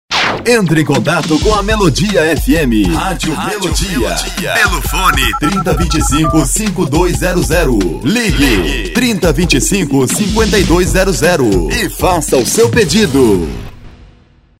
Masculino
Voz Jovem